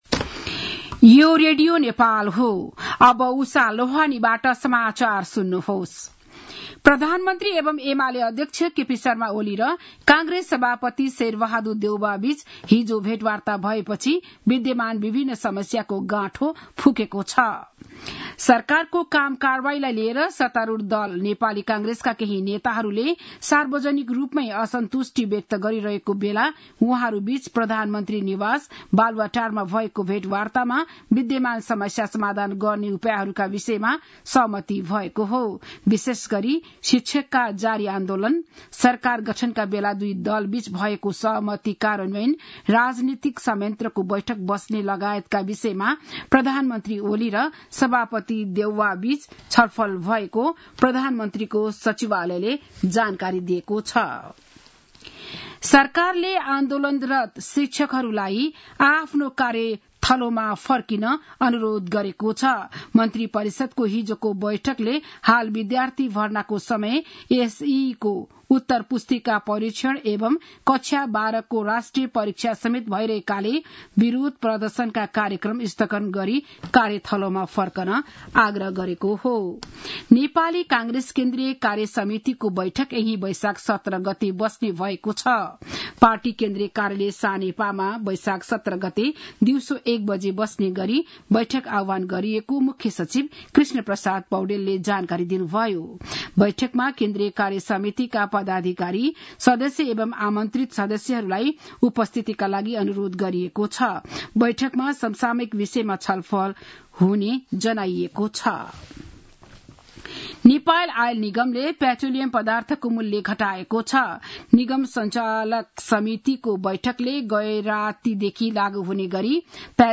बिहान ११ बजेको नेपाली समाचार : ३ वैशाख , २०८२
11-am-news-1-6.mp3